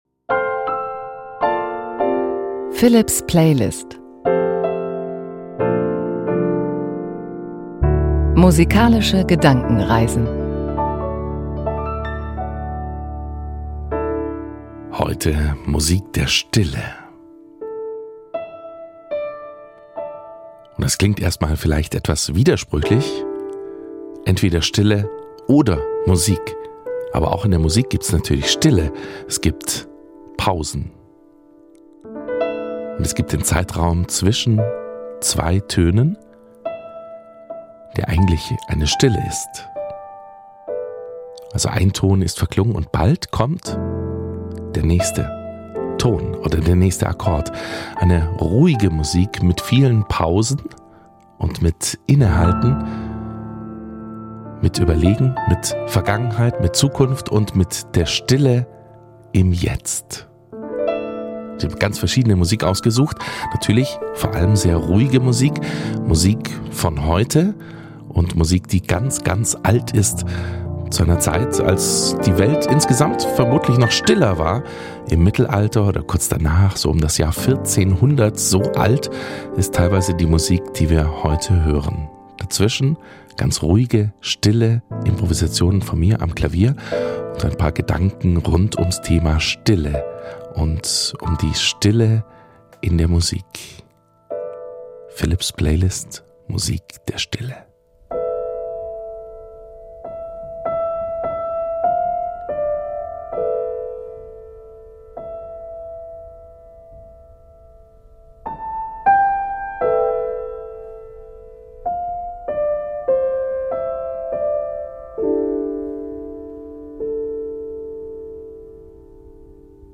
Psssst, auch die Pausen gehören zur Musik. Trotzdem bleibt es nicht ganz tonlos, aber dafür herrlich entspannt.